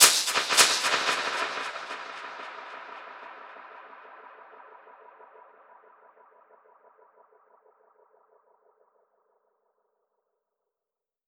Index of /musicradar/dub-percussion-samples/85bpm
DPFX_PercHit_C_85-07.wav